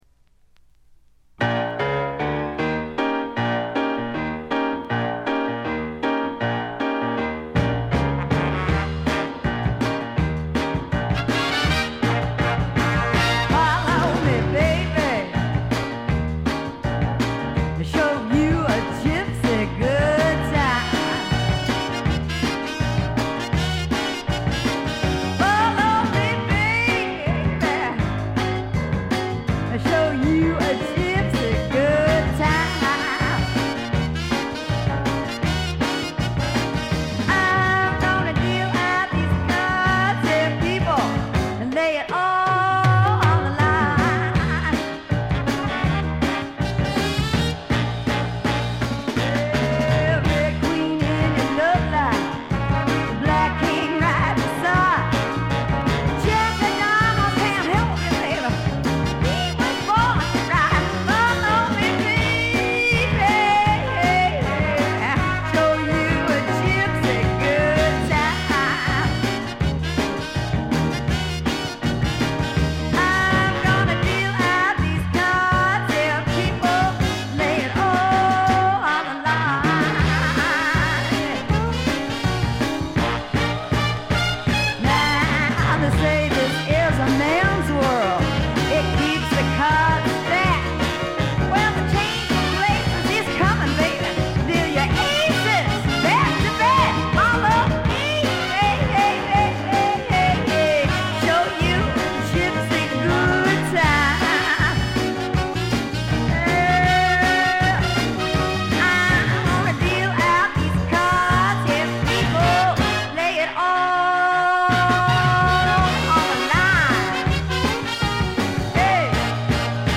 散発的なプツ音少し。
スワンプ、R&B、ジャズ、ブルース、サイケのごった煮で
ホーン・セクションを含む大世帯のバンドを狭いライヴ酒場で聴いているような強烈な圧力があります。
試聴曲は現品からの取り込み音源です。
Vocals